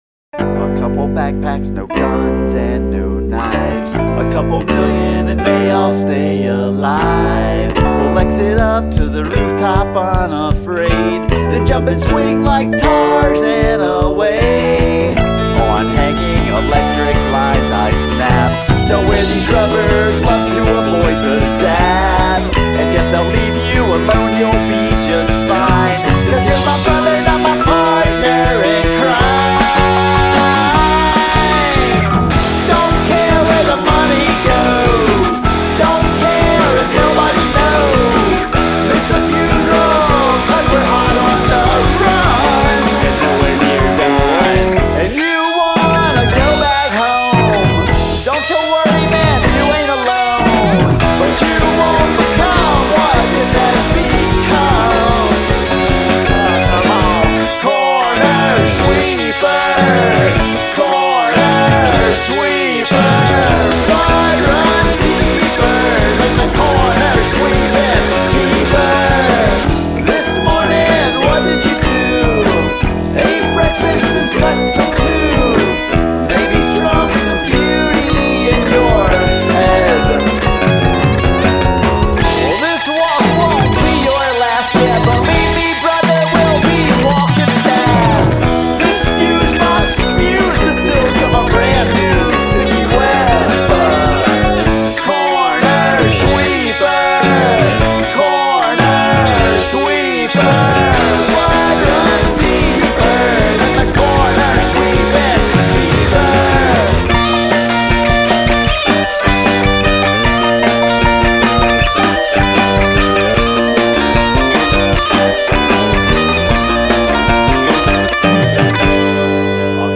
Quirkadelic Rock